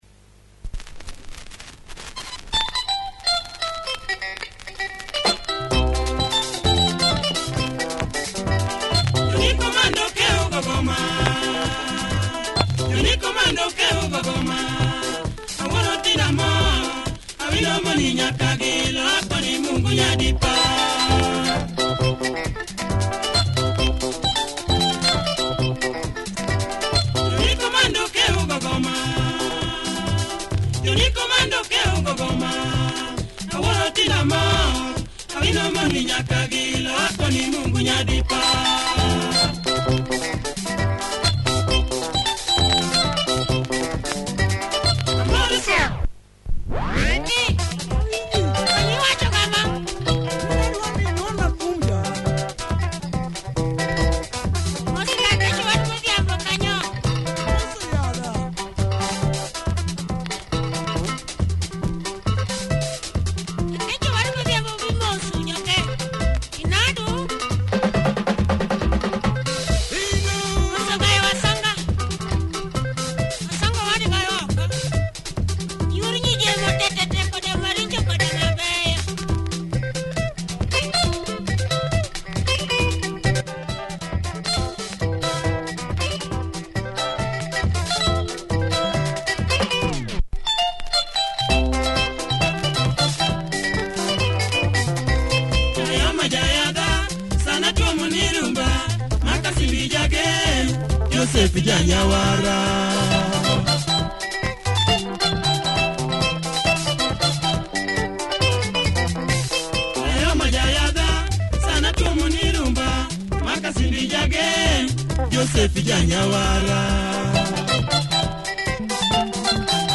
Pounding Luo Benga